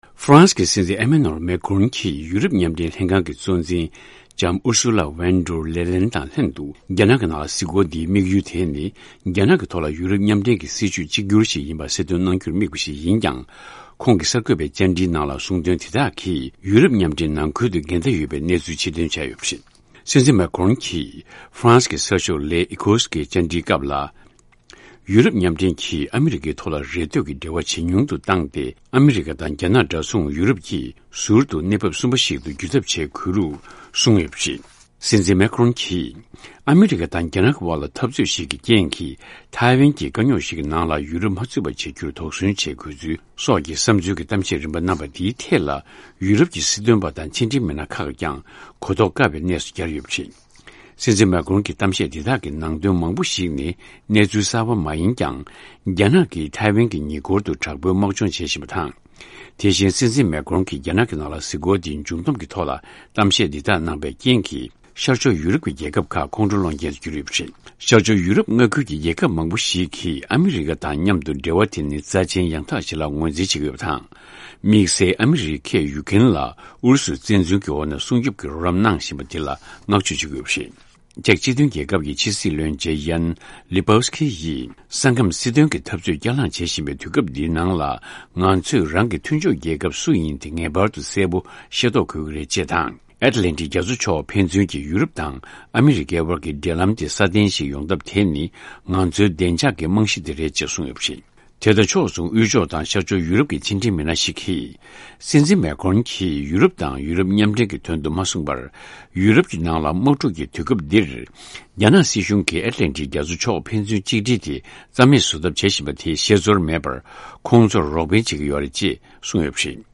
གནས་སྙན་སྒྲོན་གནང་གི་རེད།